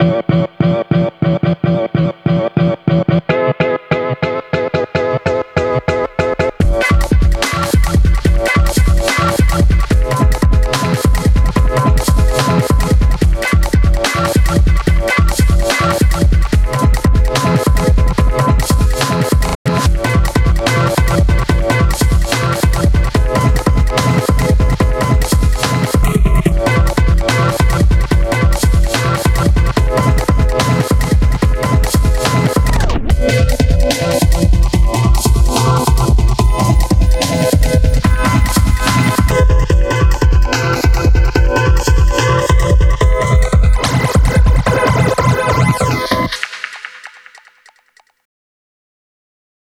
a cute little ditty i wrote during a 7 day beat challenge.